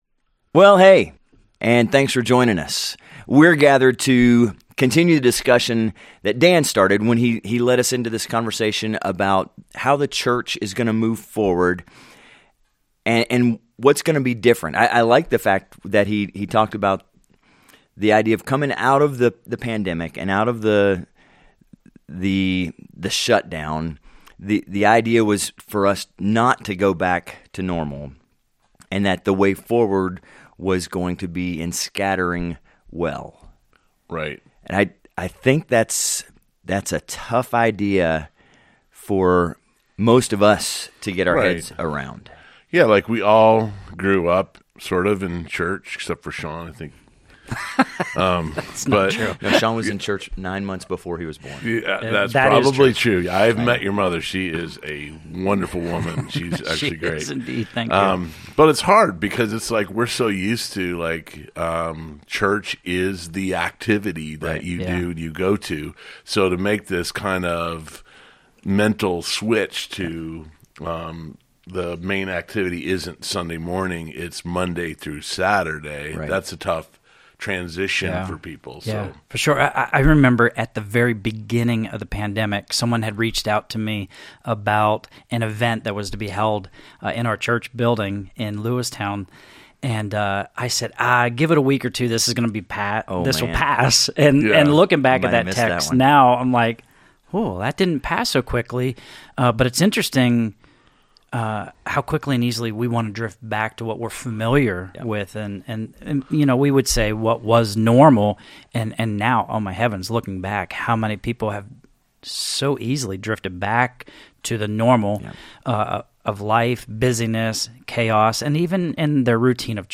Ep 16. State of the Church | A Conversation about our Strategy of Scattering Well | Calvary Portal | Calvary Portal